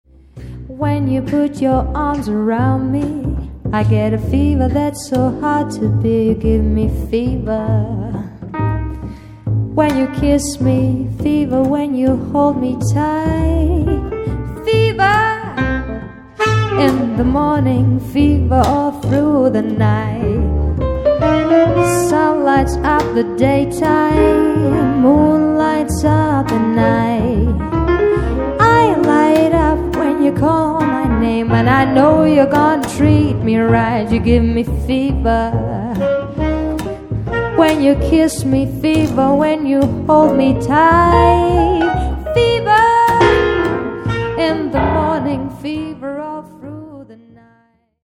Sängerin, Saxophon, Kontrabass, Piano, Schlagzeug
Swing